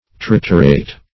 Meaning of triturate. triturate synonyms, pronunciation, spelling and more from Free Dictionary.